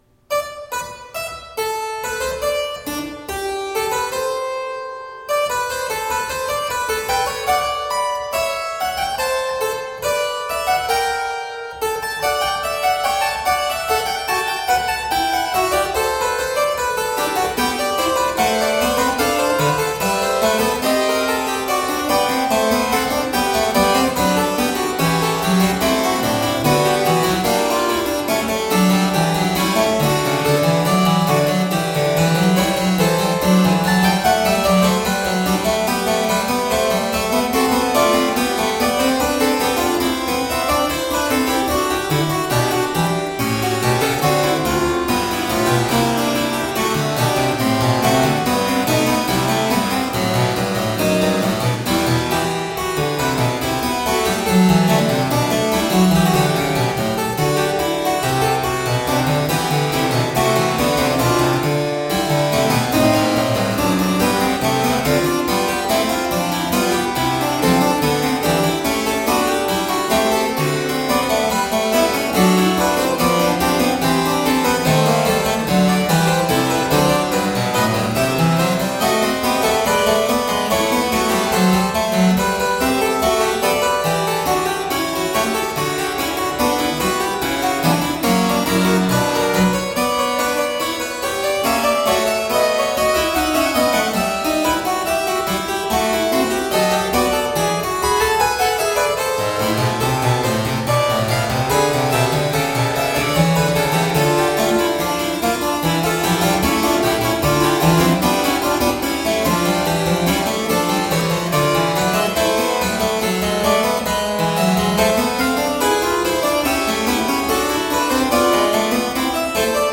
on the harpsichord - poetic and expressive.
Classical, Baroque, Instrumental
Harpsichord